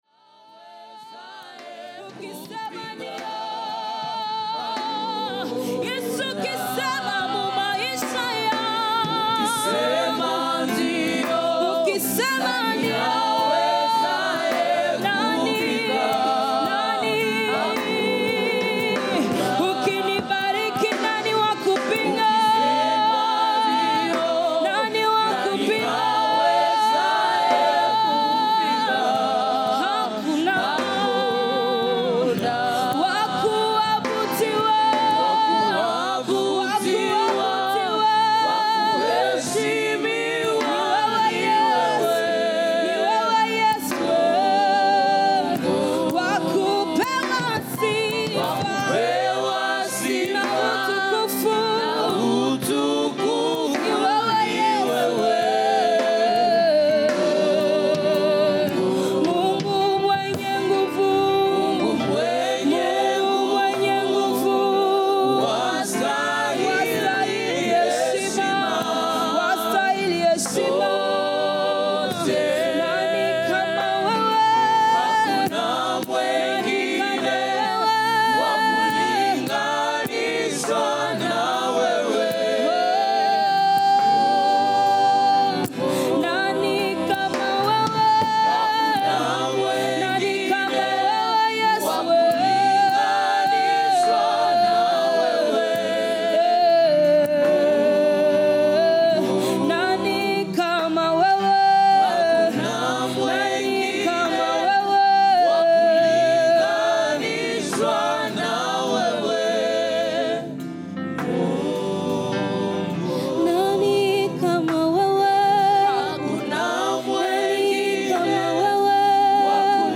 14 jours d'enseignements & Prière: ...